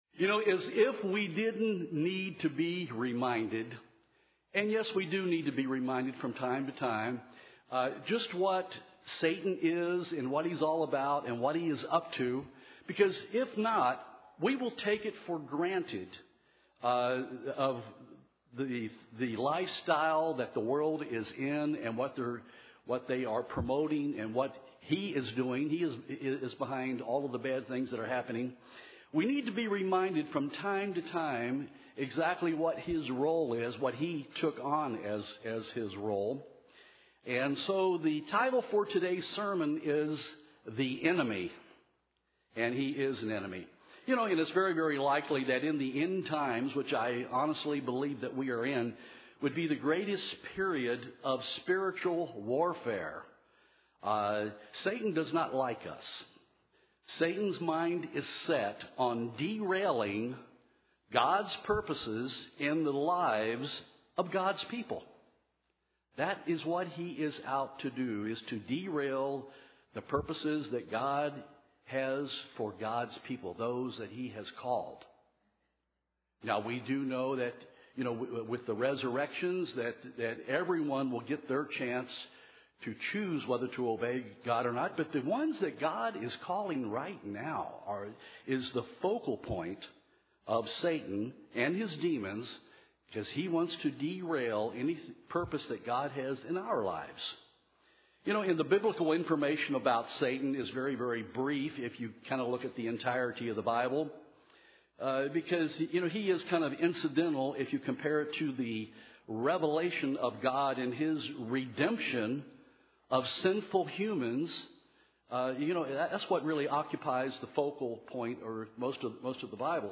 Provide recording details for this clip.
Given in Nashville, TN Knoxville, TN